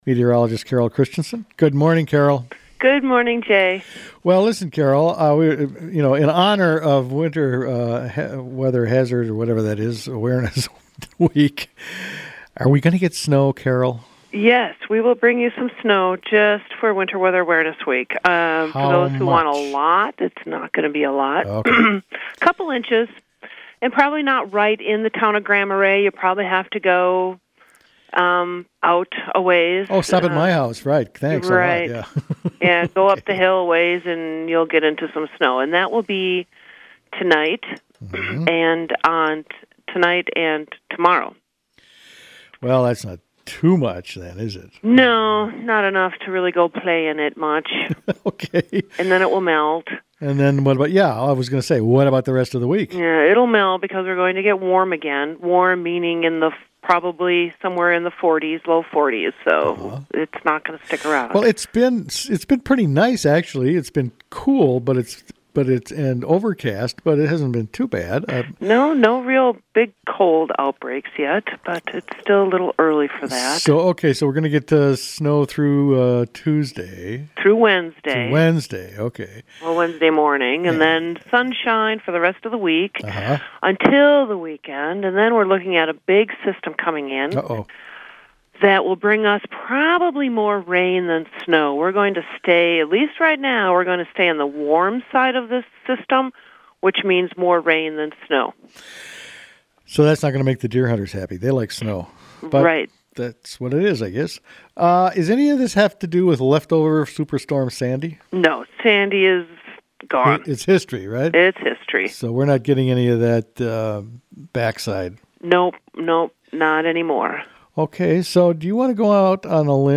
Weather forecast: a little snow on the way